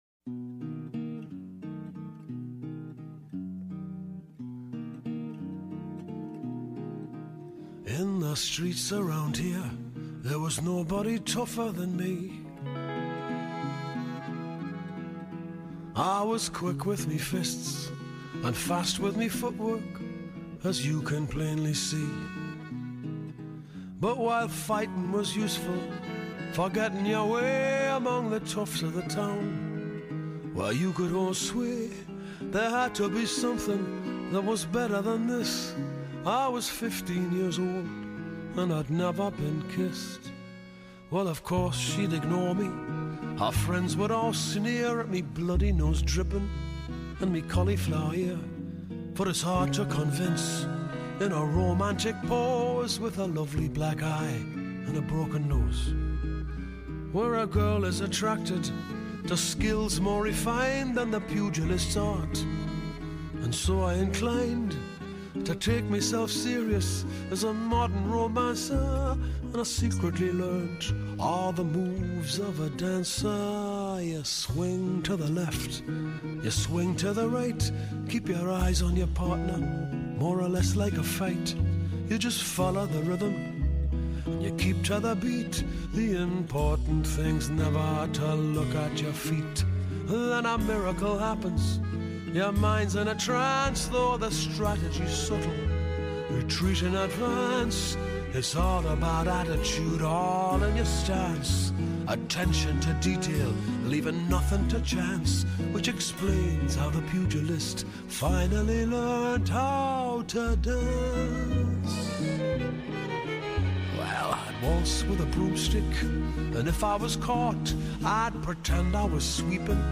Die heeft tenminste een sexy stem.